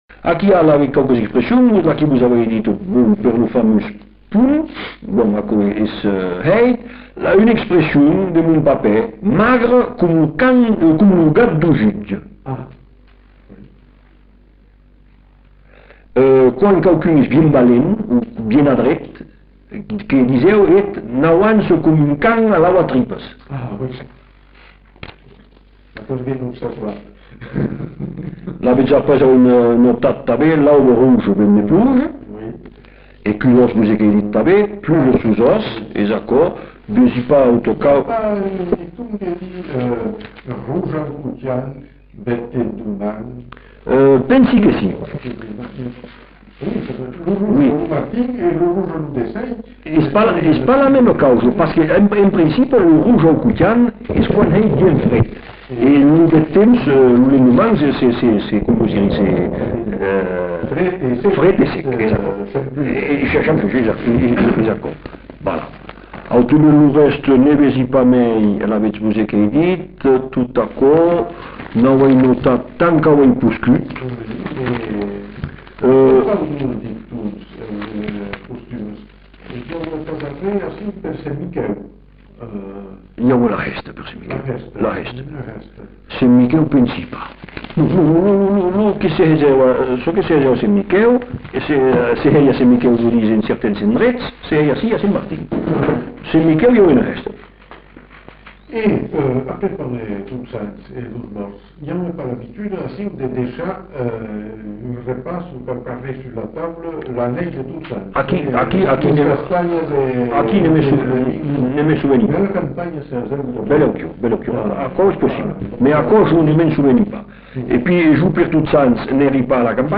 Lieu : Bazas
Genre : témoignage thématique
[enquêtes sonores] Vocabulaire occitan avec précisions sur les usages et les coutumes